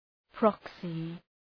Προφορά
{‘prɒksı}